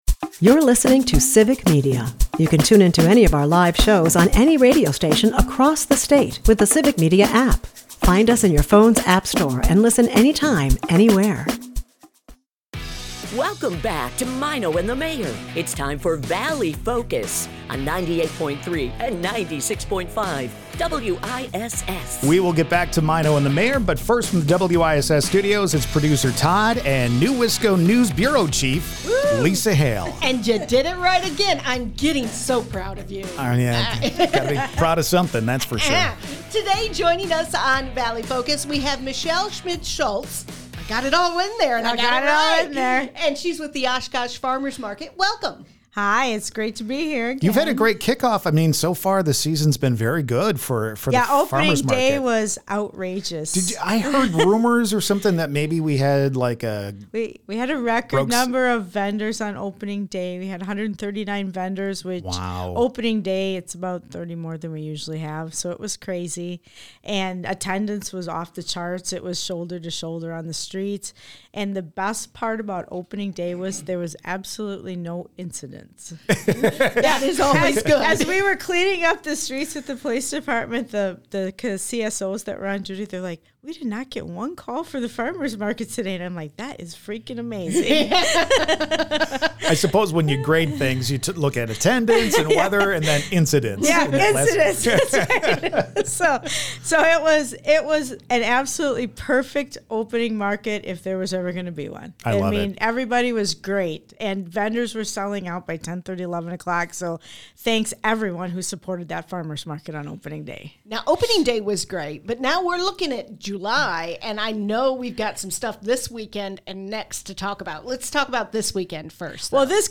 Maino and the Mayor is a part of the Civic Media radio network and airs Monday through Friday from 6-9 am on WGBW in Green Bay and on WISS in Appleton/Oshkosh.